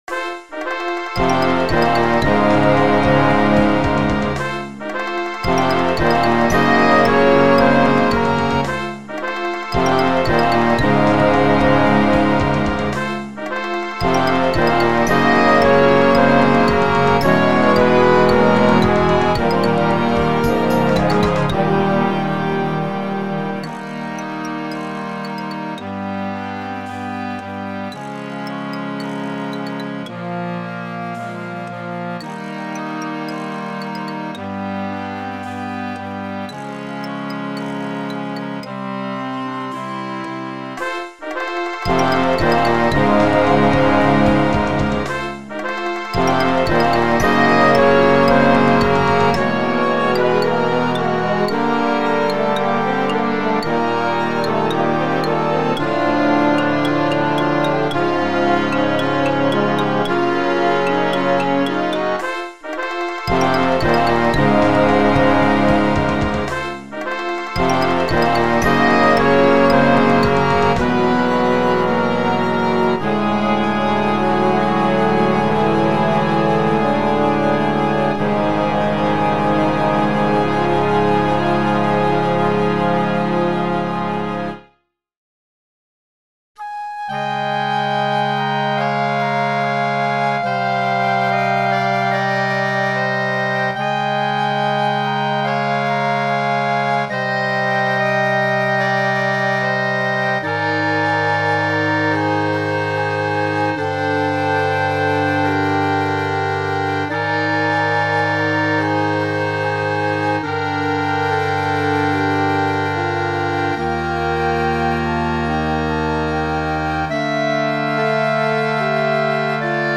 Concert Band - Festival Literature